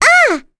Rephy-Vox_Damage_02.wav